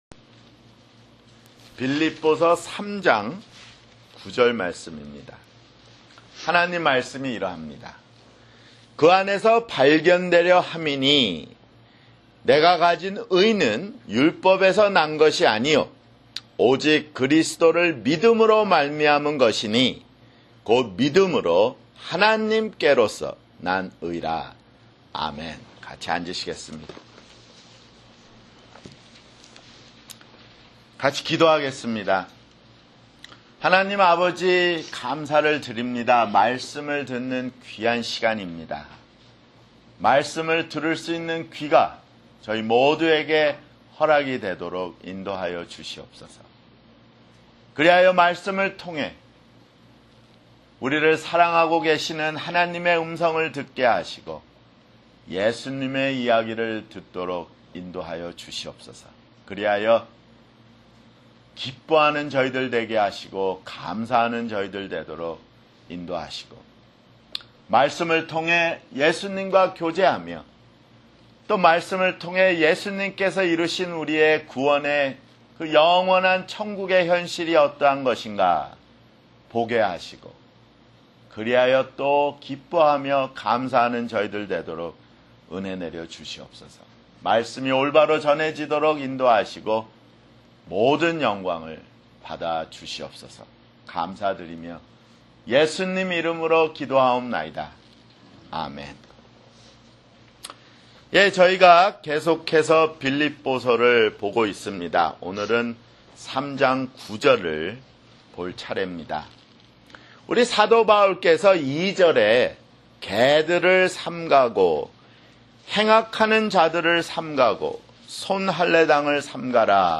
[주일설교] 빌립보서 (42)